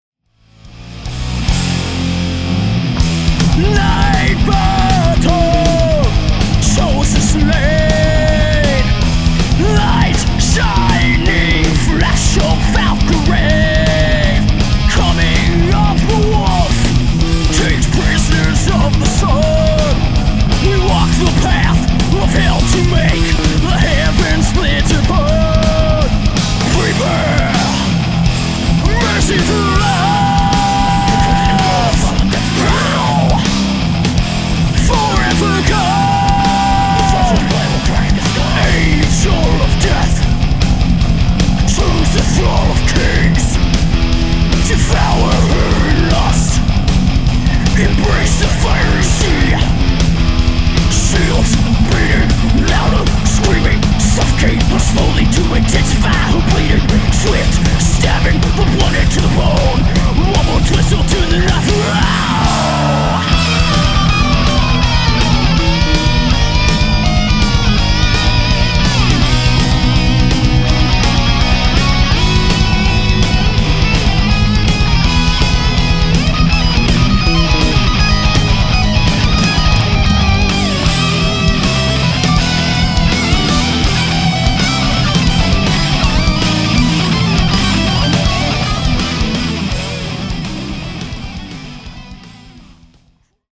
Original metal EP
Bass
Drums